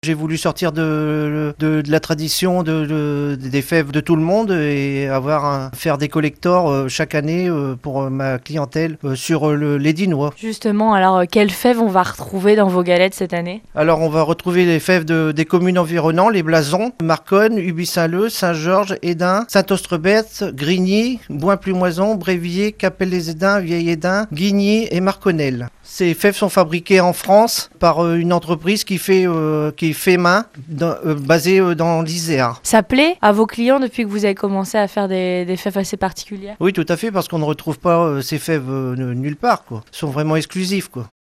Pour l'épiphanie, les boulangeries de la Côte d'Opale sont de plus en plus nombreuses à se démarquer en mettant des fèves personnalisées dans leurs galettes. Reportage à la boulangerie Leblond à Hesdin.